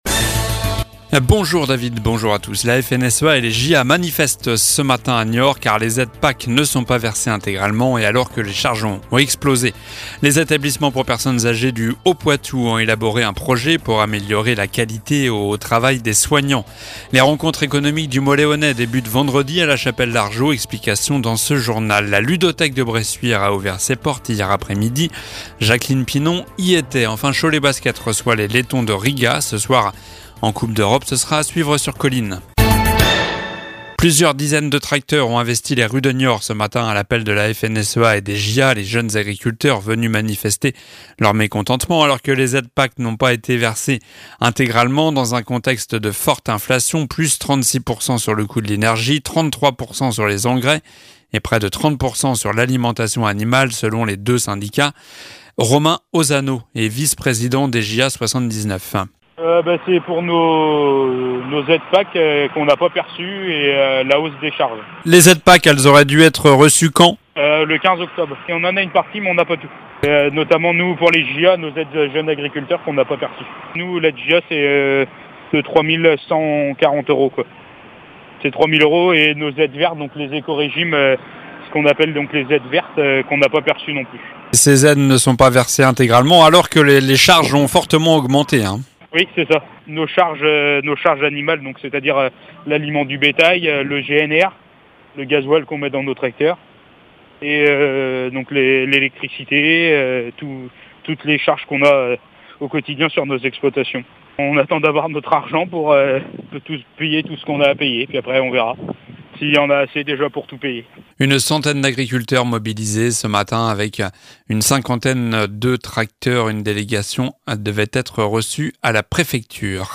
Journal du mercredi 25 octobre (midi)